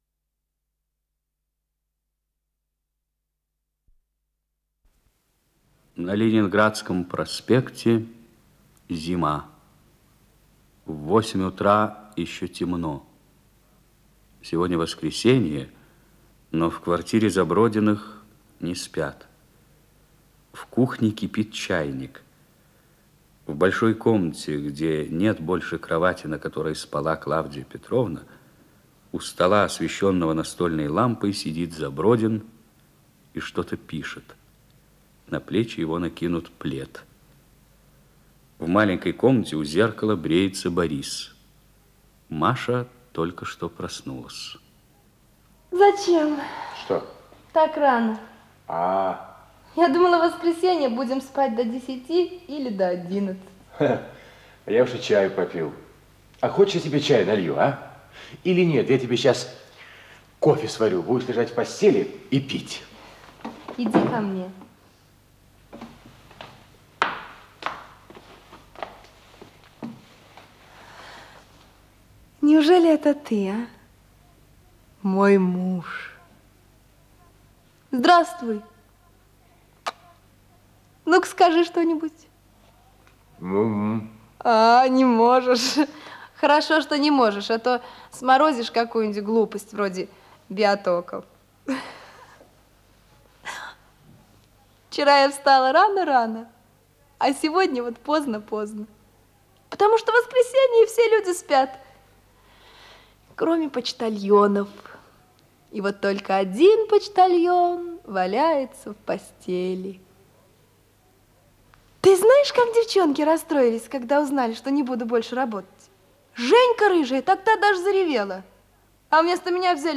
Исполнитель: Николай Мордвинов Варвара Сошальская Вадим Бероев
Название передачи Ленинградский проспект Подзаголовок Спектакль театра им. Моссовета